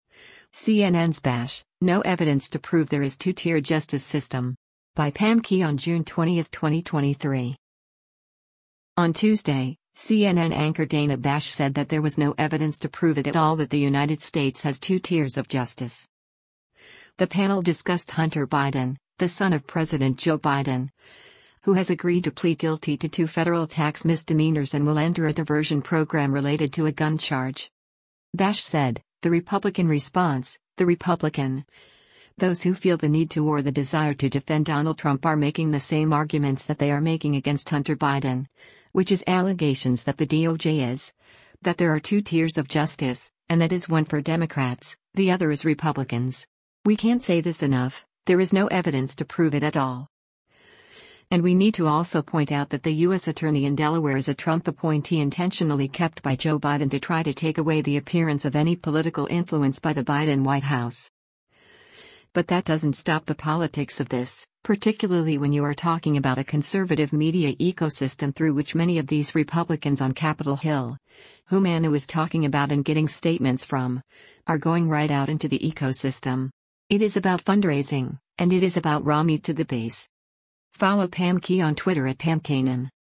On Tuesday, CNN anchor Dana Bash said that there was “no evidence to prove it at all” that the United States has “two tiers of justice.”
The panel discussed Hunter Biden, the son of President Joe Biden, who has agreed to plead guilty to two federal tax misdemeanors and will enter a diversion program related to a gun charge.